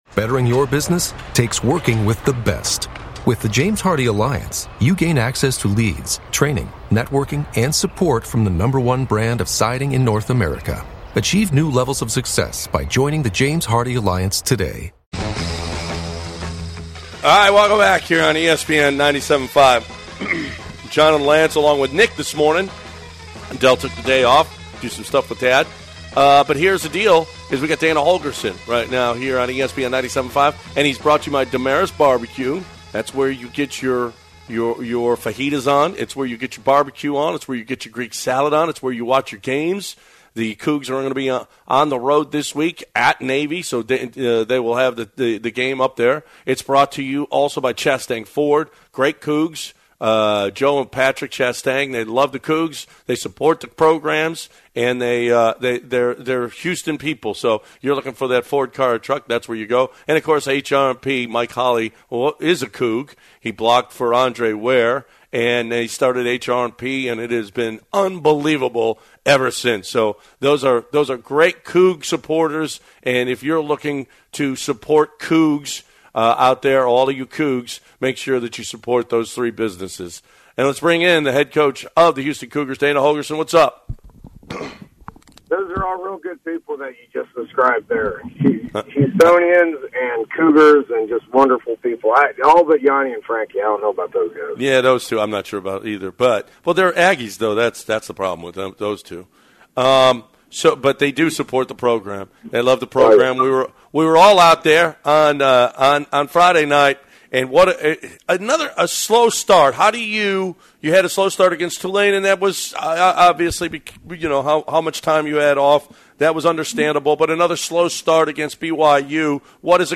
10/22/20 Dana Holgorsen Interview